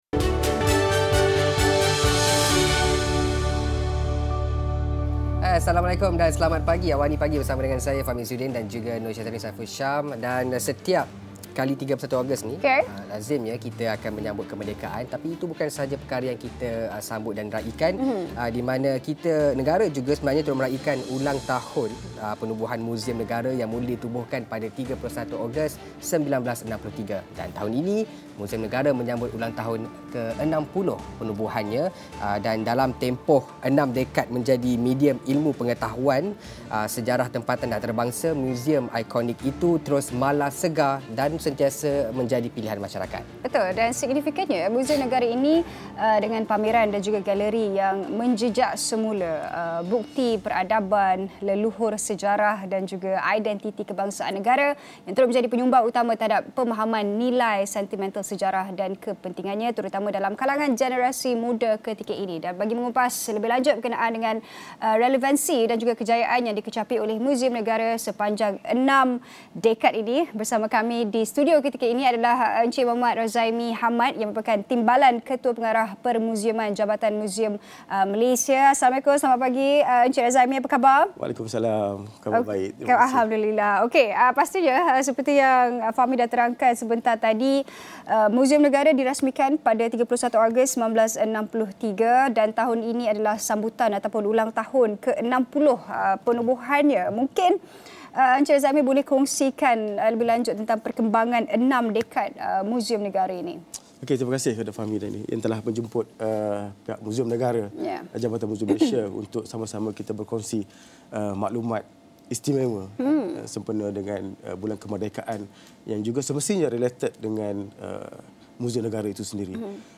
Muzium Negara, yang dirasmikan pada 31 Ogos 1963, akan menyambut ulangtahunnya yang ke-60 esok. Ikuti pengisian program menarik sempena meraikan enam dekad Muzium Negara. Diskusi